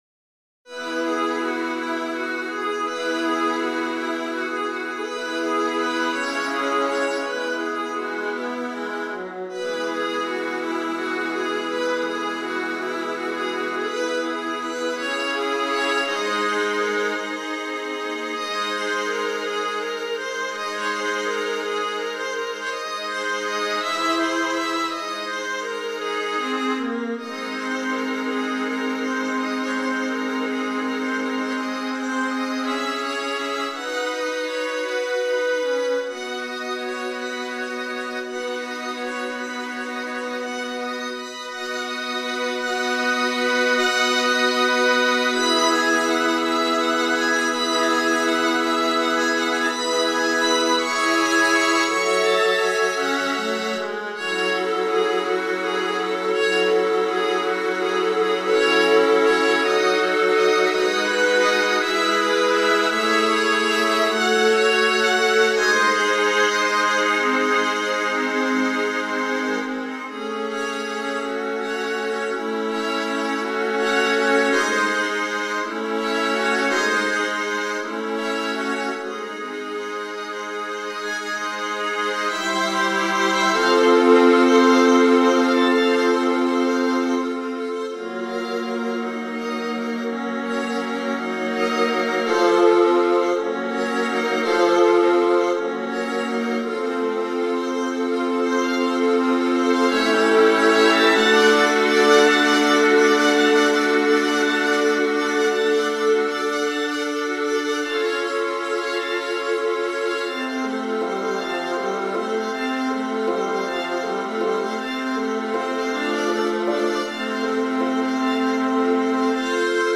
But now for the first time it is a Viola Quintet.